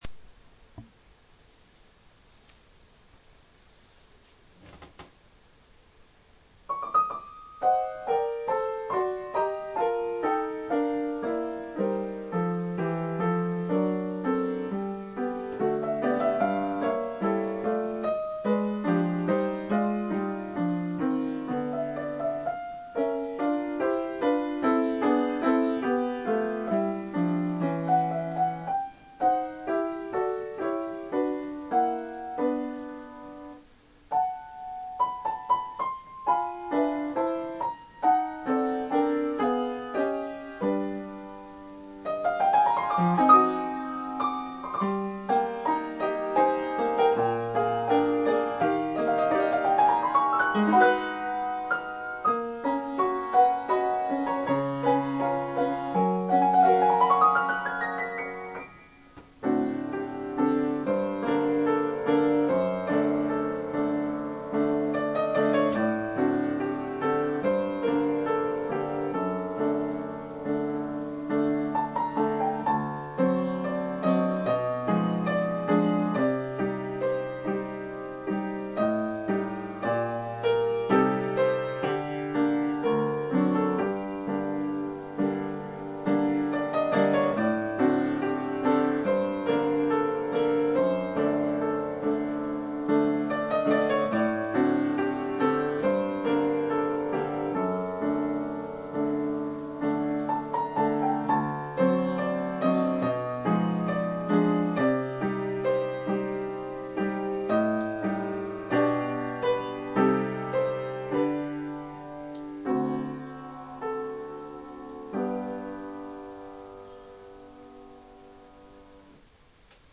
会議用の簡易録音機器なので、音楽を録ると音が割れやすく、使いにくい。